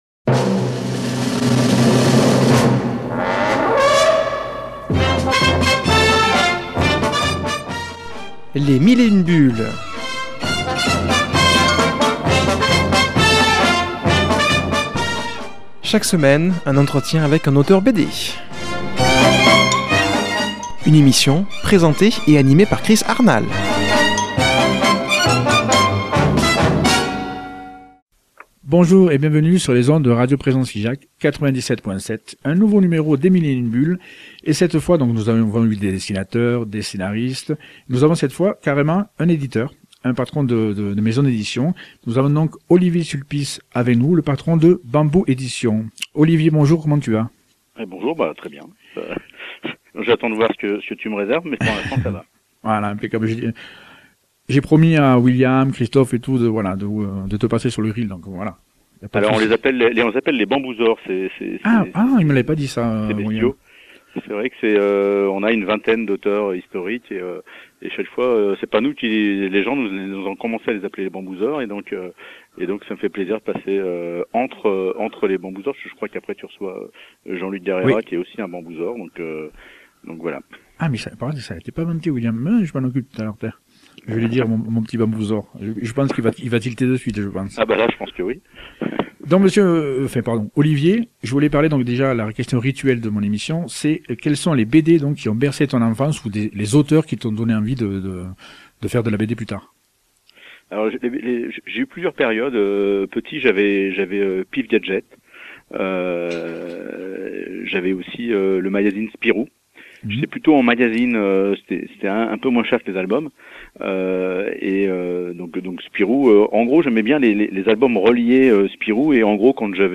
invité au téléphone